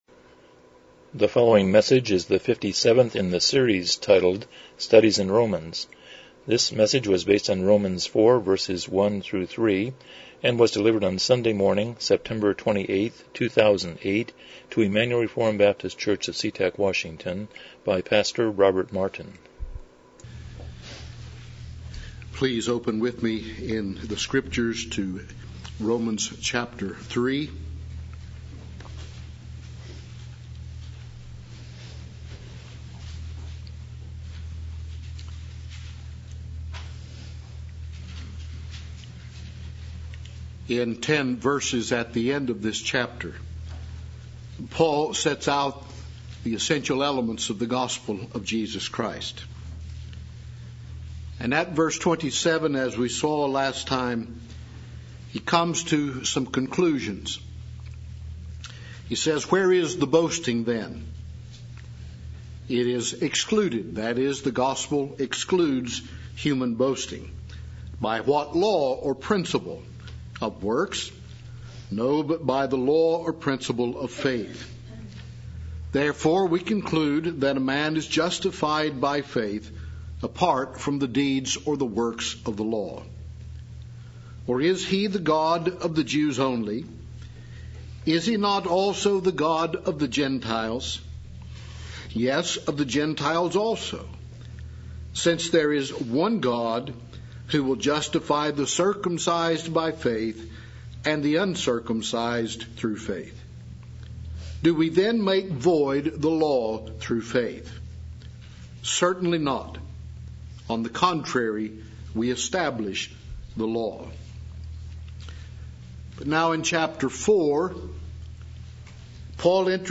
Romans 4:1-3 Service Type: Morning Worship « 43 Chapter 5.7